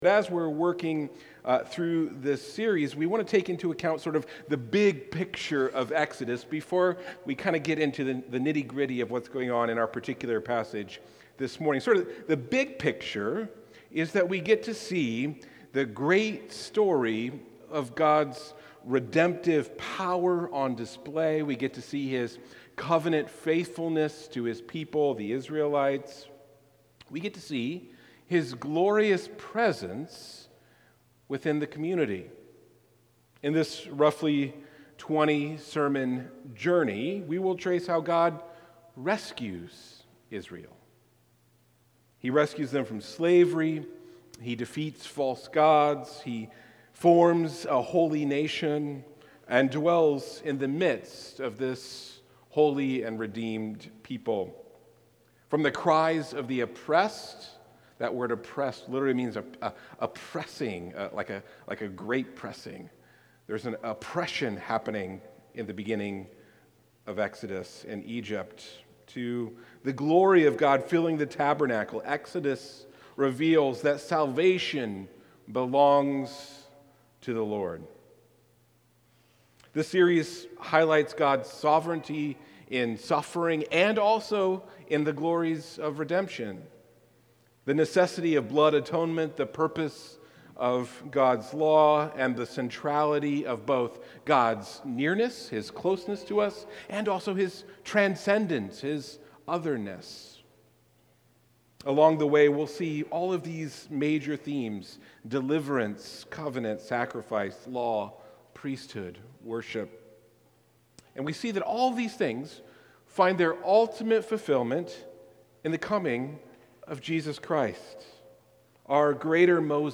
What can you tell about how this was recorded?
This sermon emphasizes that mission flows from worship, courage flows from God’s character, and the burning bush points forward to Christ—the Holy One who draws near without consuming His people.